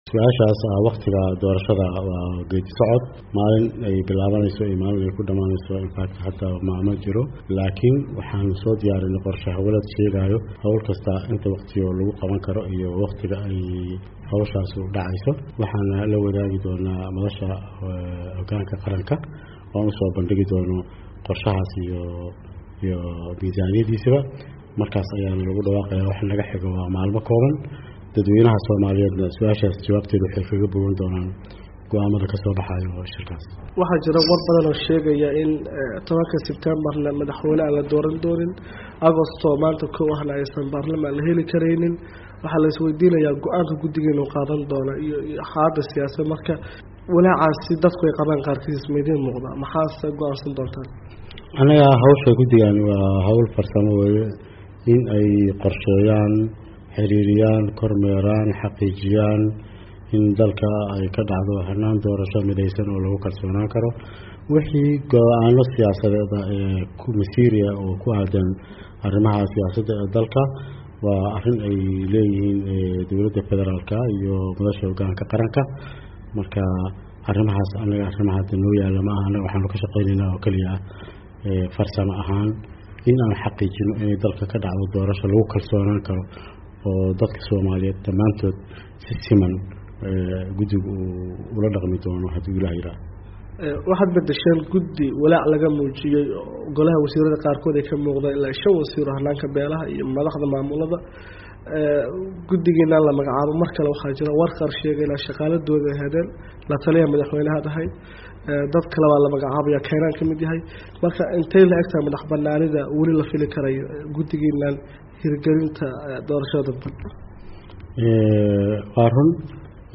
Dhagayso VOA oo waraysatay Gudoomiye Cumar Dhagey